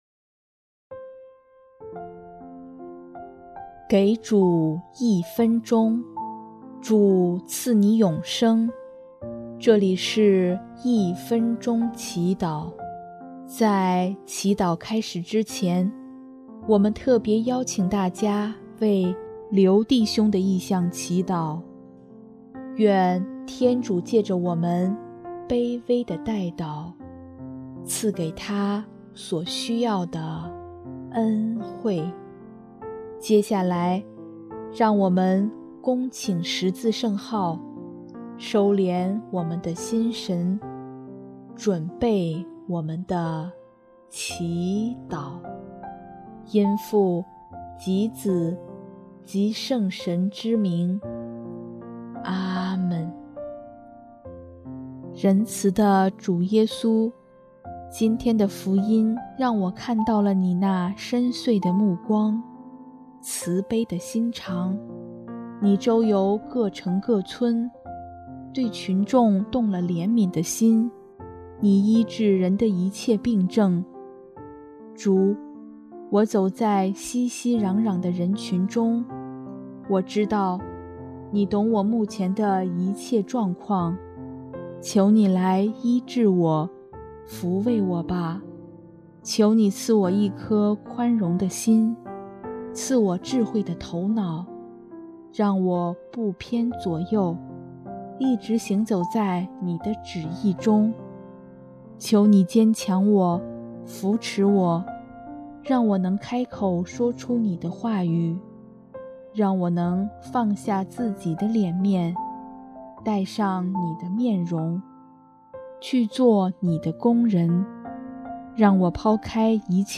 【一分钟祈祷】|12月6日 主，求您医治我
音乐：第四届华语圣歌大赛参赛歌曲《万有真原万物之光》